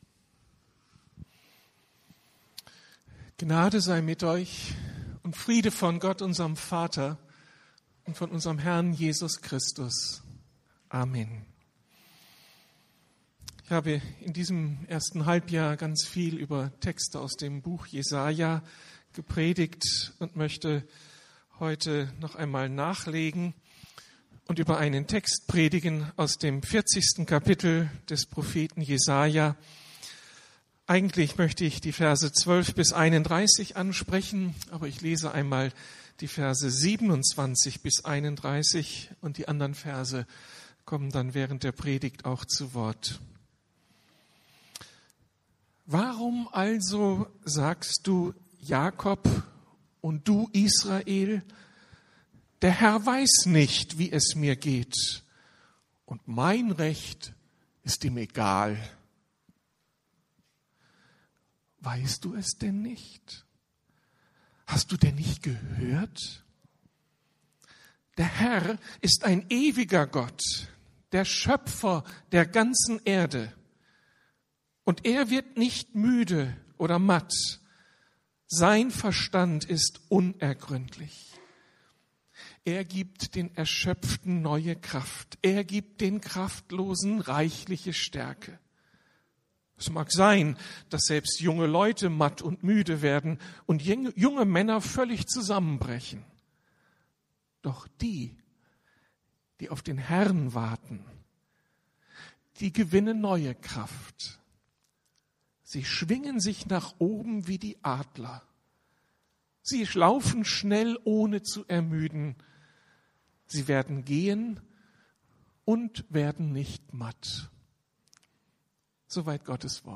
Kopf hoch! ~ Predigten der LUKAS GEMEINDE Podcast